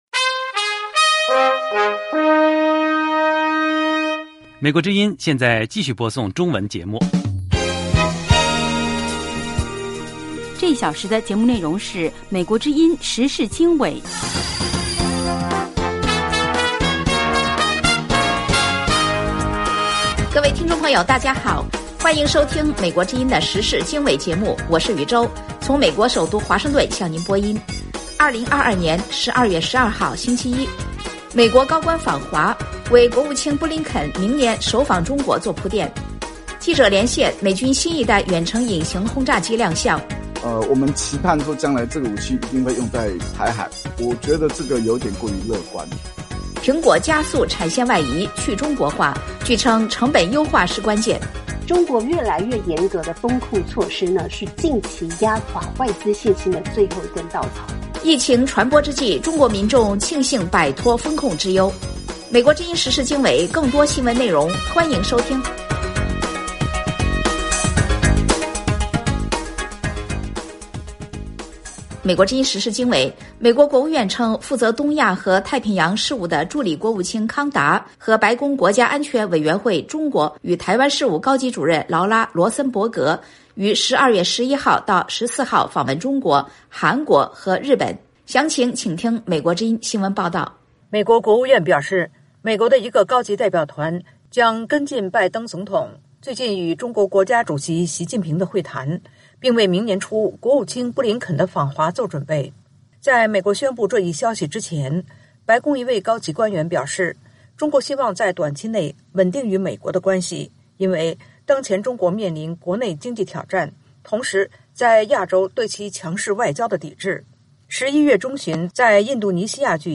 2/记者连线：美军新一代远程隐形轰炸机亮相。3/记者连线：苹果加速产线外移“去中化”，据称成本优化是关键。4/疫情传播之际，中国民众庆幸摆脱封控之忧。